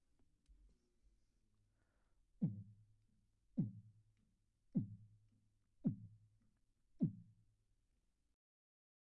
Beatbox Library " 人类的长低音鼓
描述：人造低音鼓用于音乐混音。
Tag: 5maudio17 人类 循环 UAM 节拍 贝司